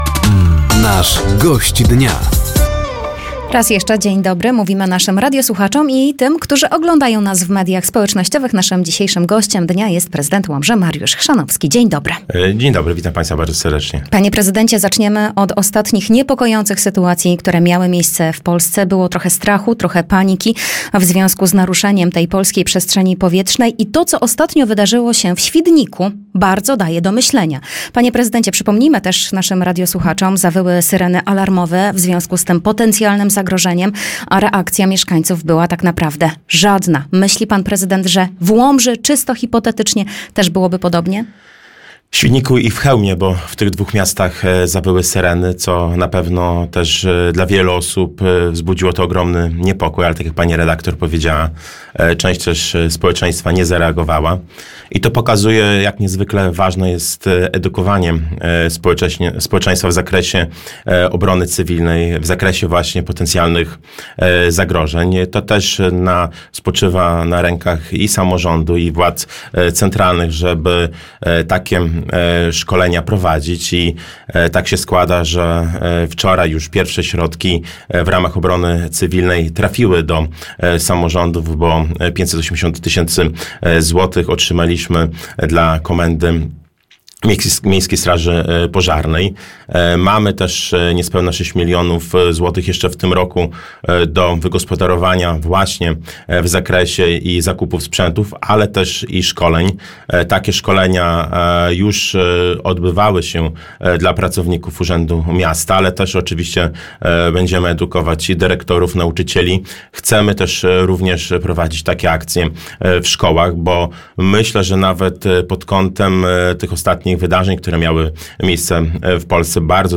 Zapraszamy do wysłuchania rozmowy z prezydentem Łomży, Mariuszem Chrzanowskim.